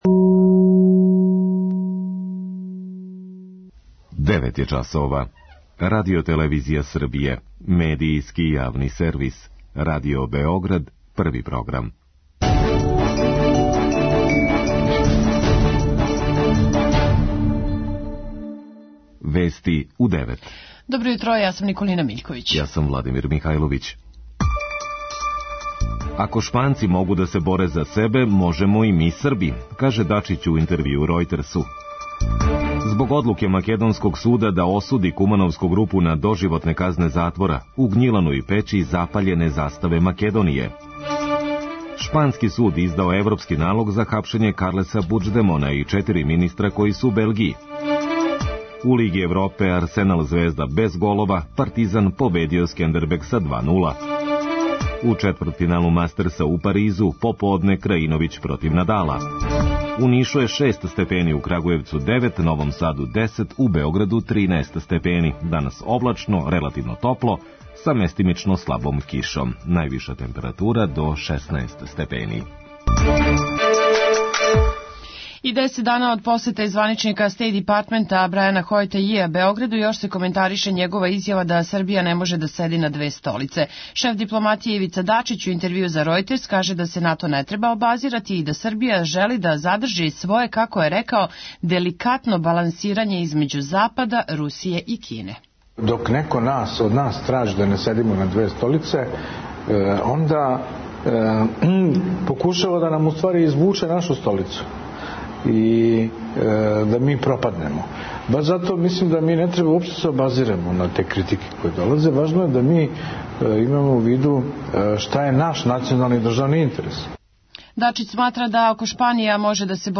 преузми : 3.77 MB Вести у 9 Autor: разни аутори Преглед најважнијиx информација из земље из света.